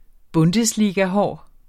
Udtale [ ˈbɔndəsˌliːga- ]